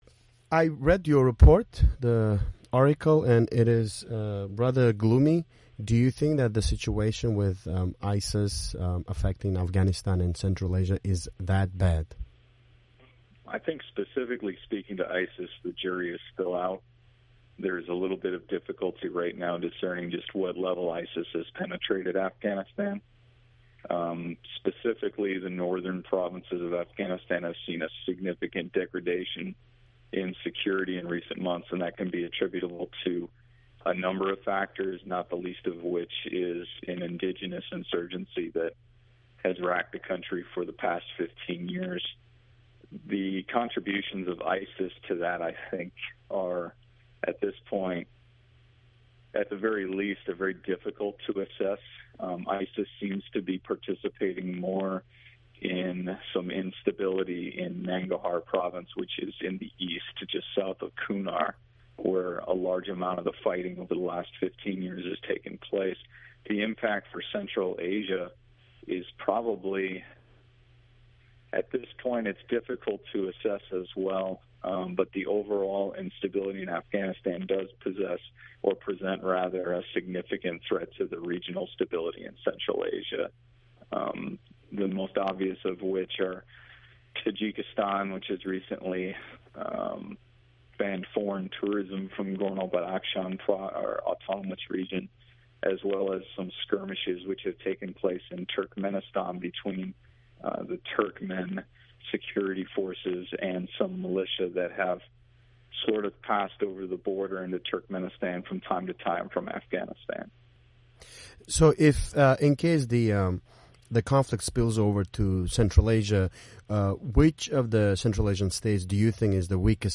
Bu intervyuning ingliz tilidagi ko'rinishi.